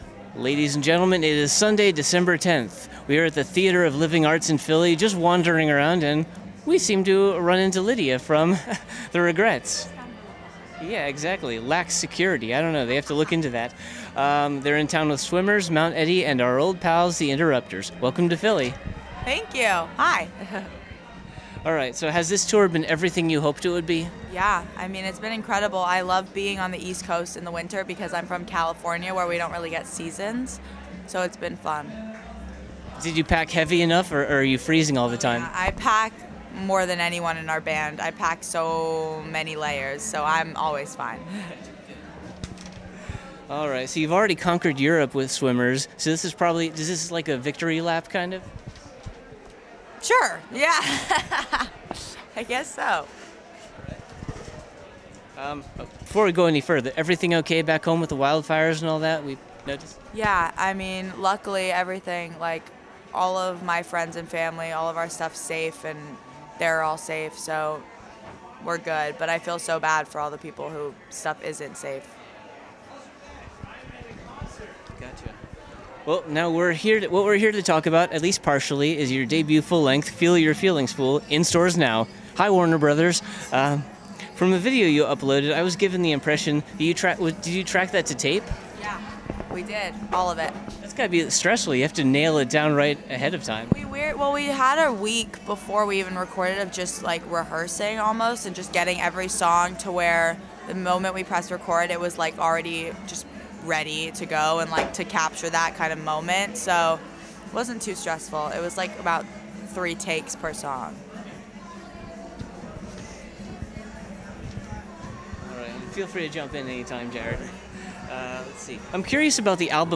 Exclusive Interview: The Regrettes at the TLA (Ep 81)
Theater of Living Arts – 12/10/17